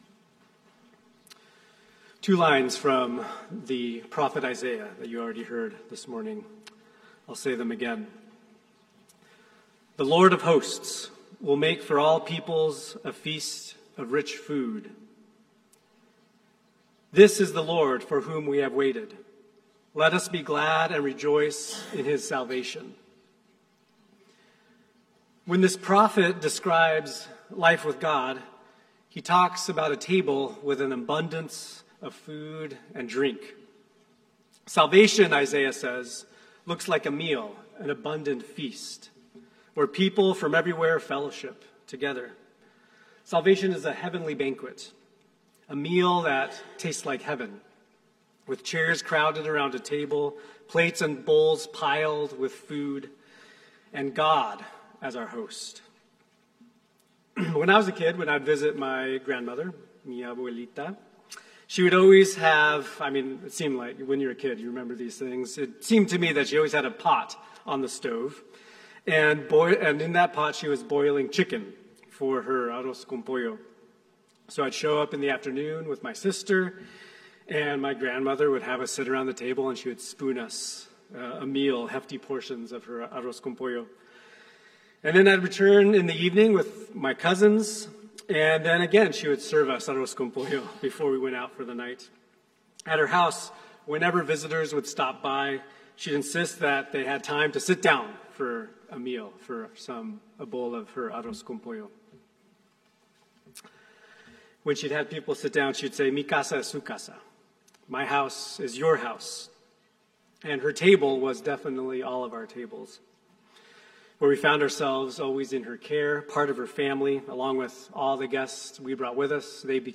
Each week, we share reflections meant to nurture your spiritual life. The homilies and talks are from services at St. Paul's Episcopal Church in Cary, North Carolina.